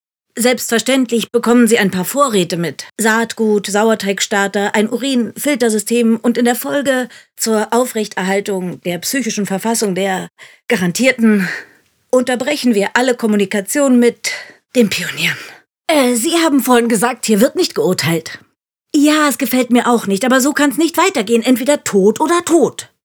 Synchron (verworren)